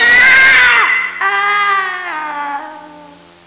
Groan Sound Effect
Download a high-quality groan sound effect.
groan.mp3